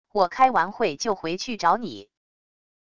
我开完会就回去找你wav音频生成系统WAV Audio Player